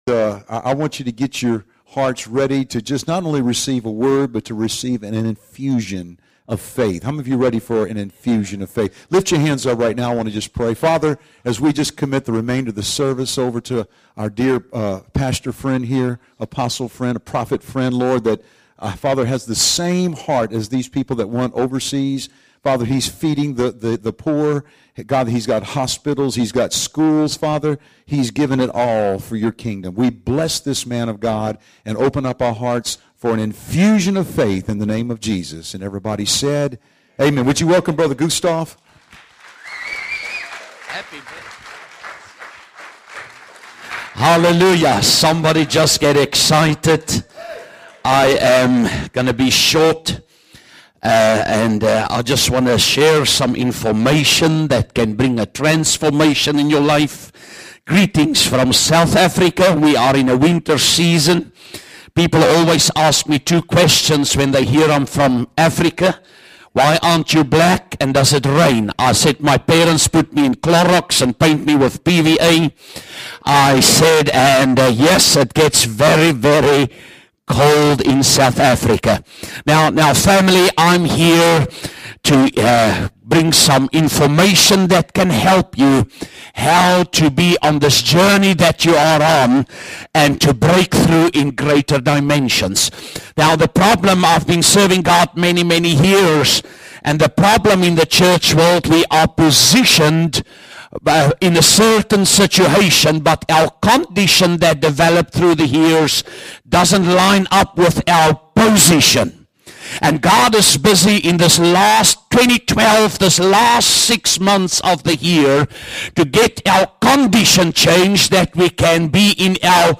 Sermons Archive - Page 18 of 20 - PraiseChurch